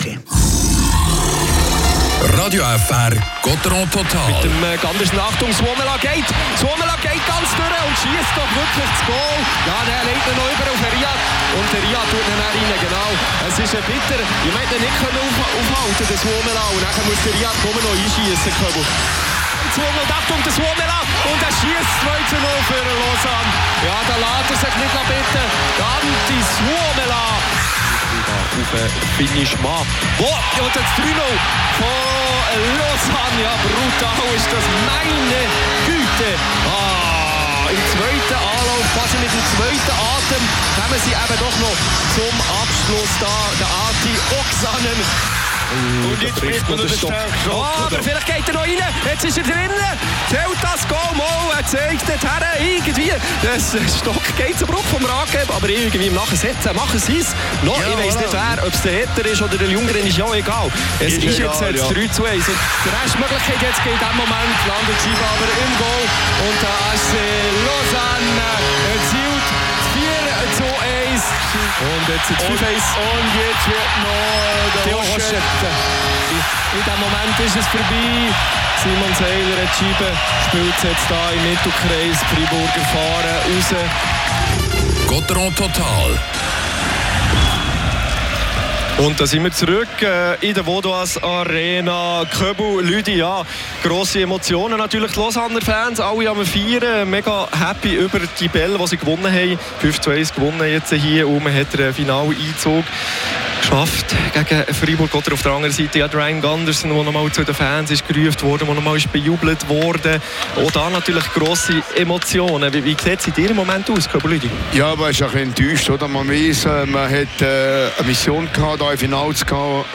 Gottéron verpasst den Finaleinzug im letzten Spiel gegen Lausanne. Die Analyse der Experten und die Interviews mit Christoph Bertschy und Julien Sprunger jetzt im Gottéron Total.